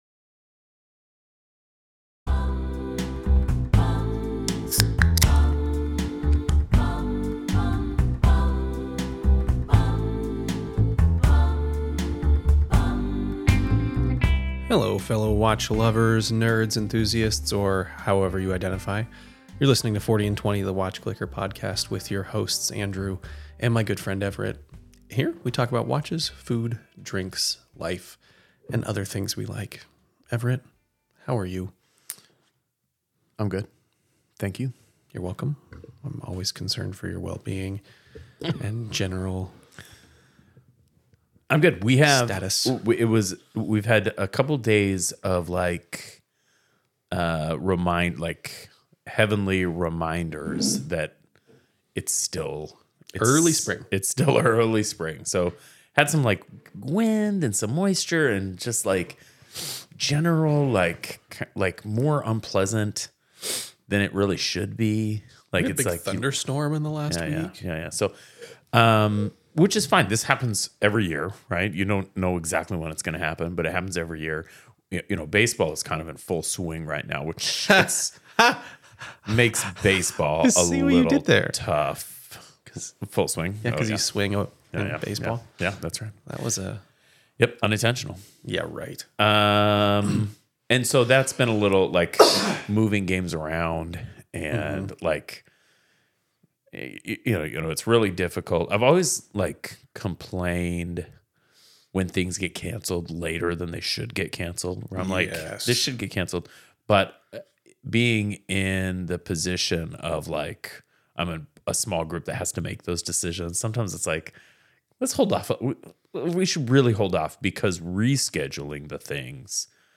debate design choices, sizing decisions, and market positioning while maintaining their signature casual, humorous tone.